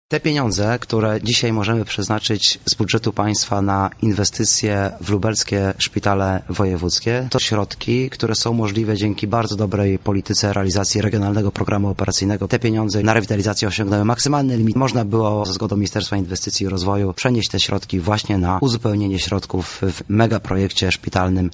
O tym skąd znalazły się fundusze które będą przeznaczone na szpitale mówi Artur Soboń